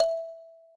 kalimba_e.ogg